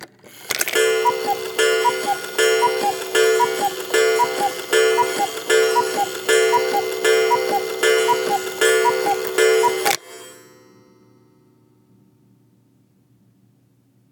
Cuckoo Clock Chimes 12
10 bell bells cathedral chime chimes church church-bell sound effect free sound royalty free Animals